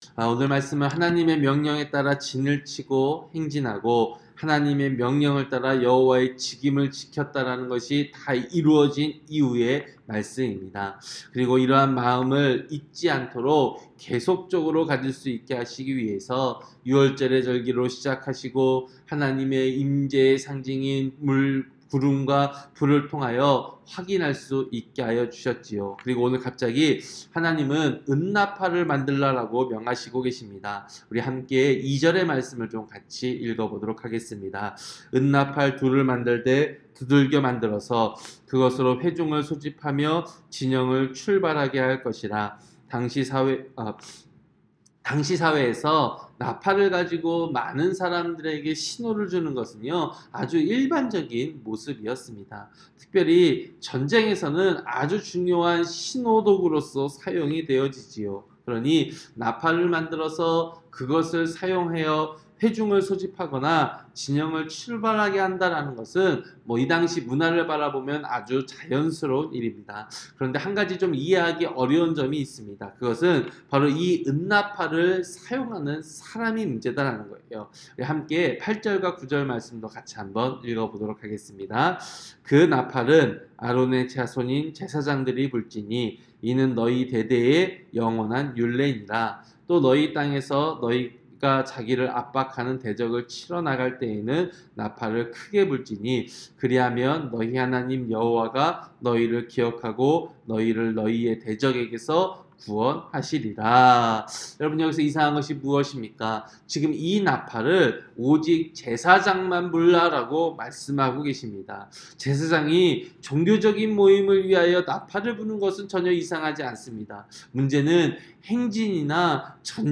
새벽기도-민수기 10장